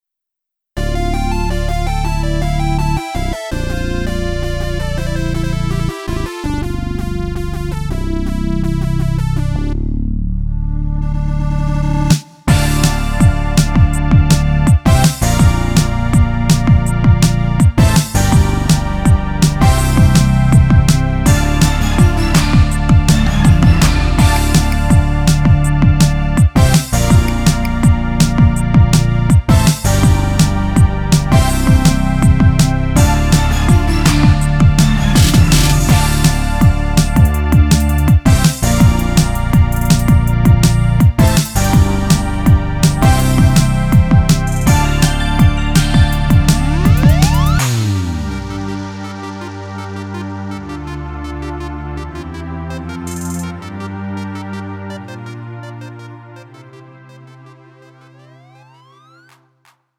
음정 -1키 3:33
장르 가요 구분 Lite MR